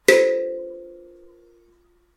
Звуки глюкофона
Глюкофон звучит в ноте С5